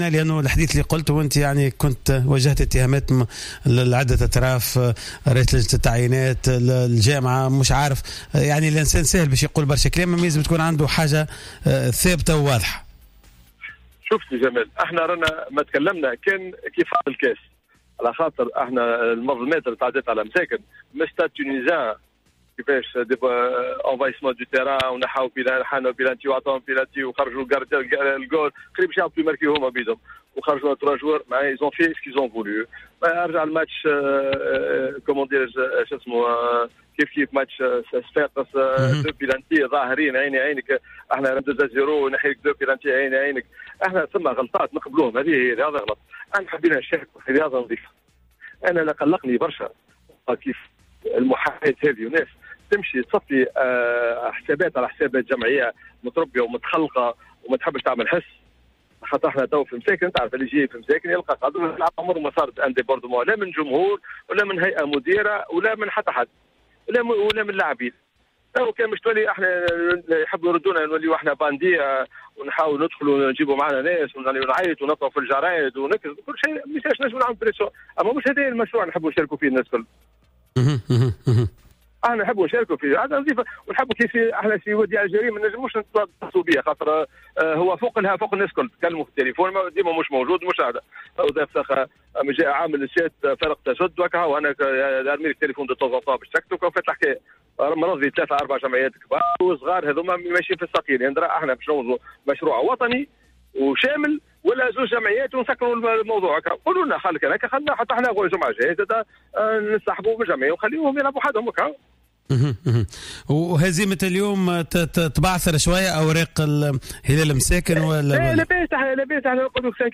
خلال مداخلة في حصة راديو سبور